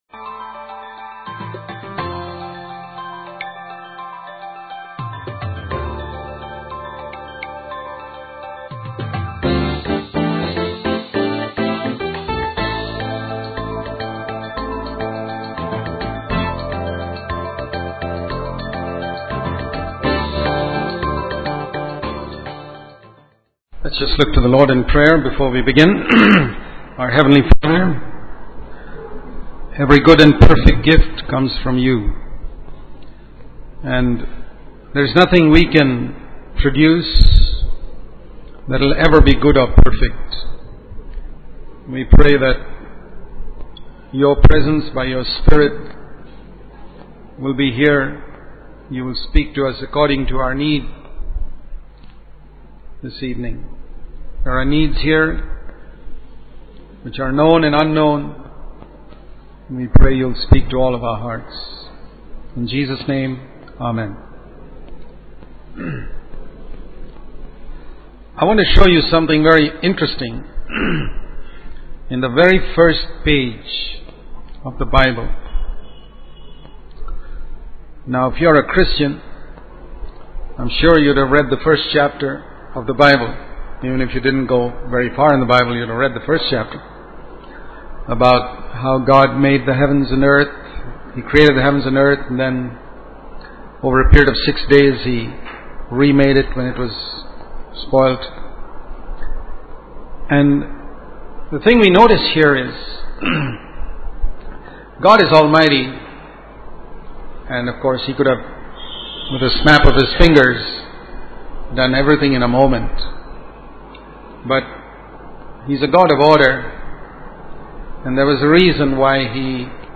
In this sermon, the preacher uses the analogy of a small boat in a vast ocean to describe the challenges and uncertainties of the world we live in. He emphasizes the importance of relying on God as our only source of help and guidance.